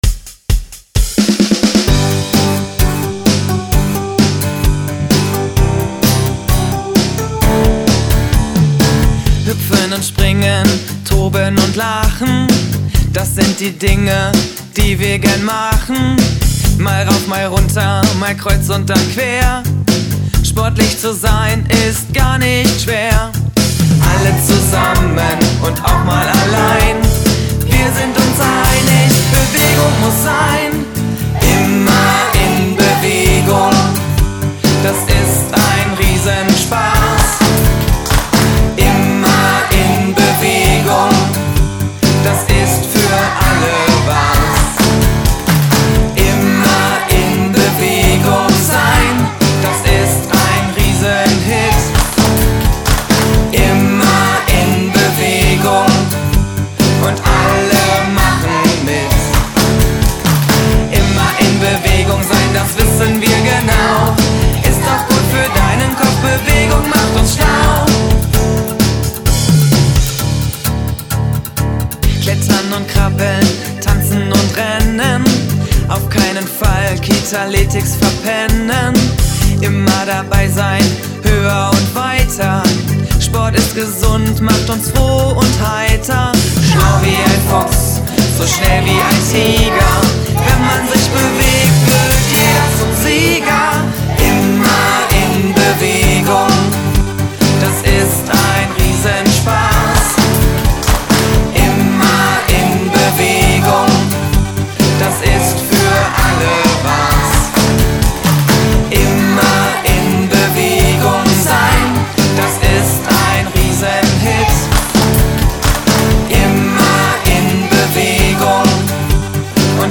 Eine Sängerin, die ab und zu als Sub mit meiner Band gesungen hat, hat dann auch spontan dem Song ihre Stimme gegeben.
Ich selbst bin ein wenig im Background zu hören und natürlich haben die Kinder der AWO-Kita *An der Witwe* fleißig mitgesungen.